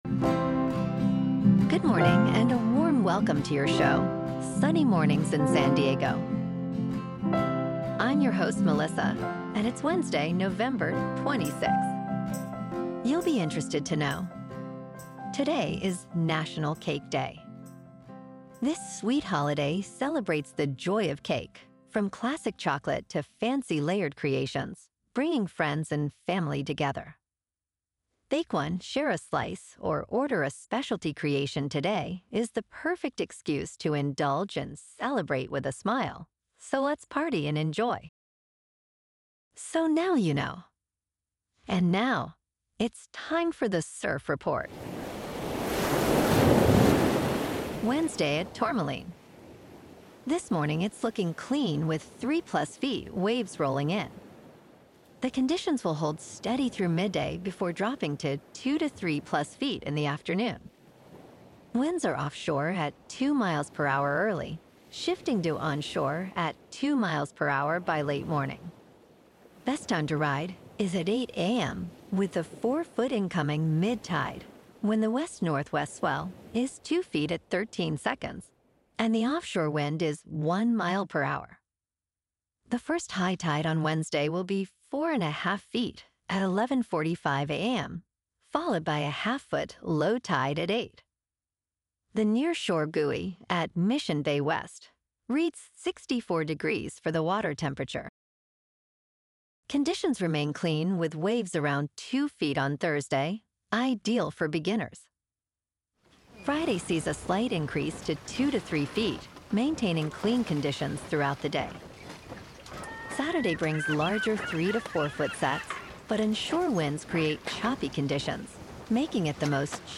The #1 Trusted Source for AI Generated News™